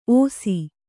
♪ ōsi